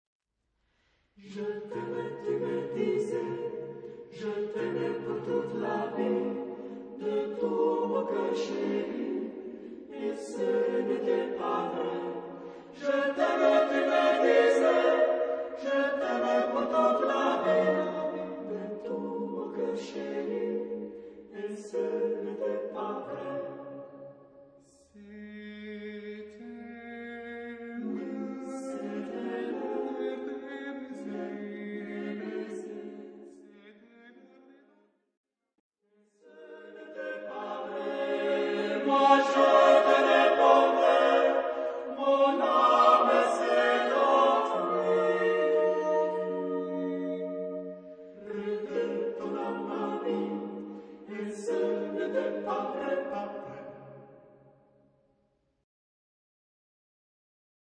Profane ; Tango ; Chanson d'amour
SATB (4 voix mixtes )
Tonalité : do majeur